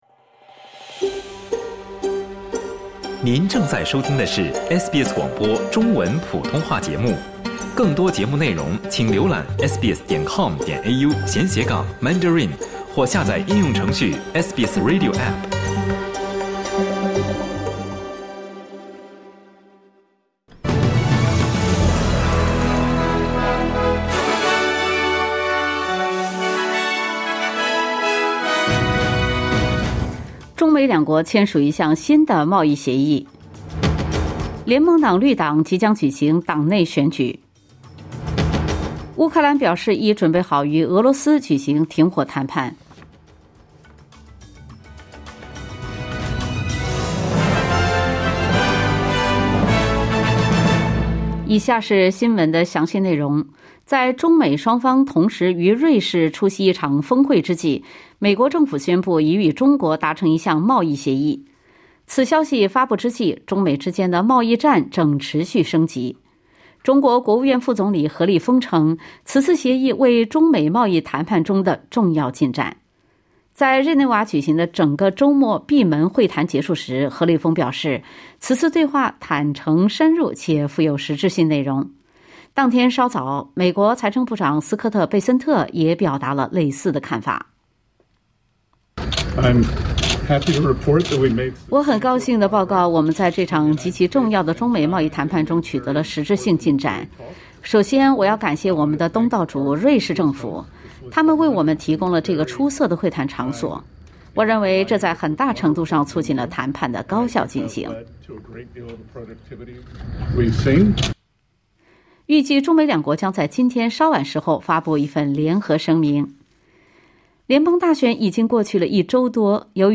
新闻快报